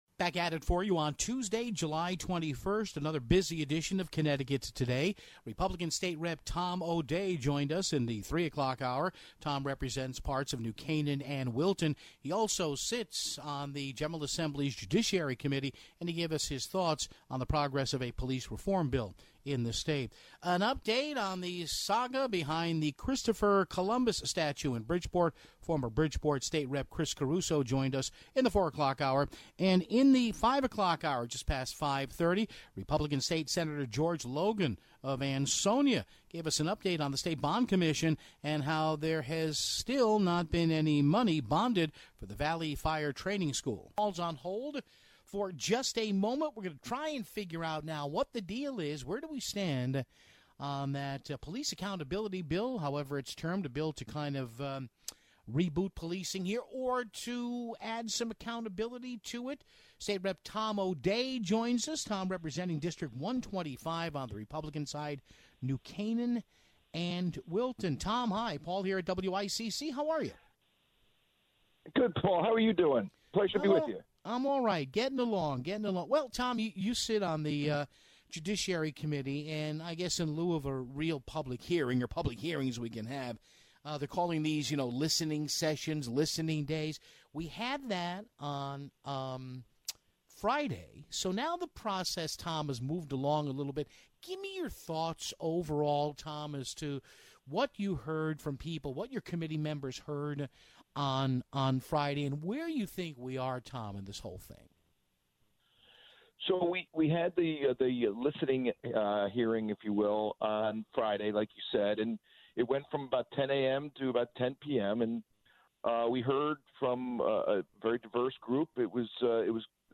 Former State Rep Chris Caruso called in to give us an update on the latest with the Christopher Columbus statue in Bridgeport.